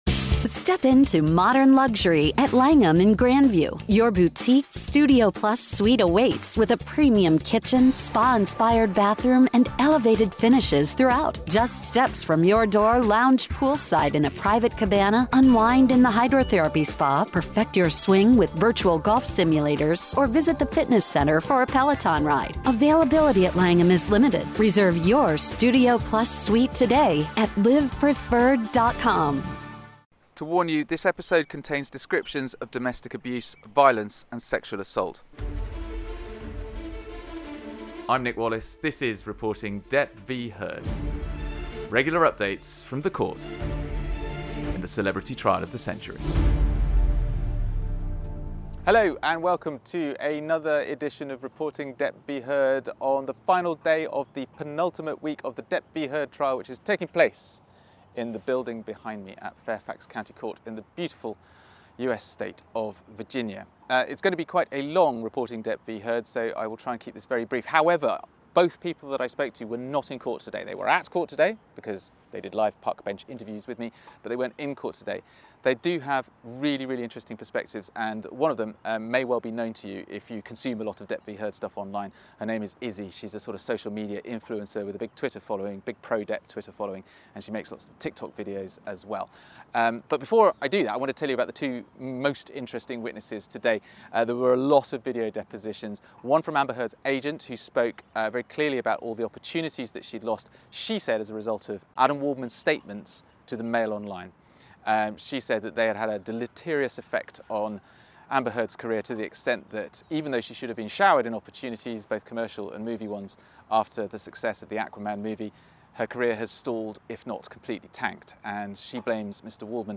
There are two Park Bench interviews in this episode.